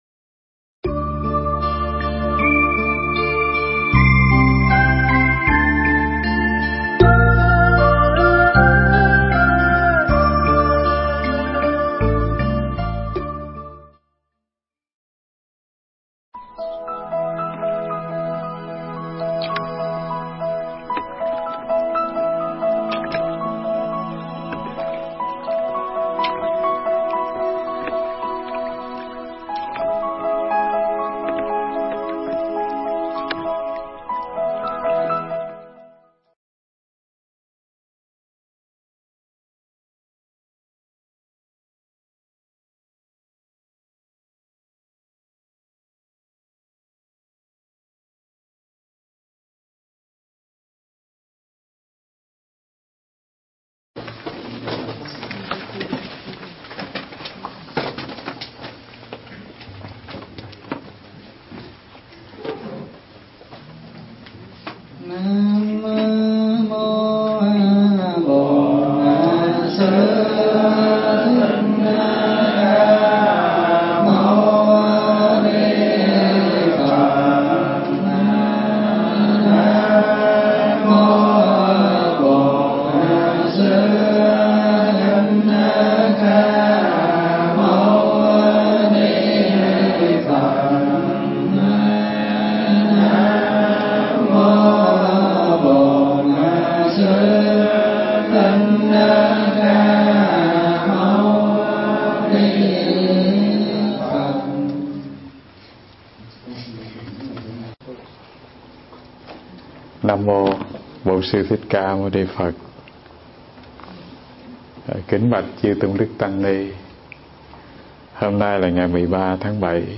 Nghe Mp3 thuyết pháp Các Định Chuẩn Của Một Giảng Sư